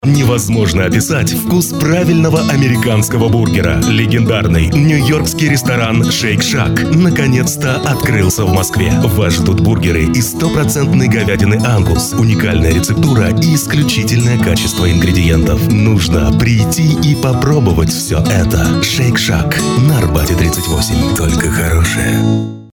Аудиореклама ресторана знаменитой американской сети бургерных Shake Shack